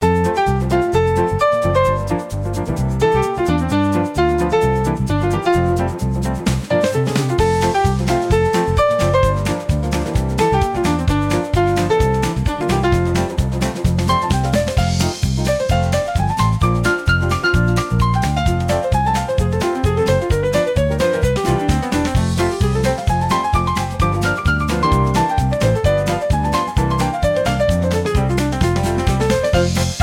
two instrumentals